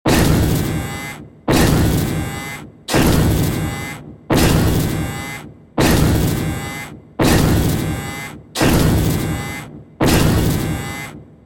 Robotic Walk Sound Effect
A robot walks at a moderate pace, with the sound of footsteps and the hum of motors moving its legs. Mechanical footsteps. The futuristic sound design makes it perfect for video games, animations, cartoons, and other multimedia projects.
Audio loop.
Robotic-walk-sound-effect.mp3